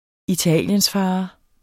Udtale [ iˈtæˀljənsˌfɑːɑ ]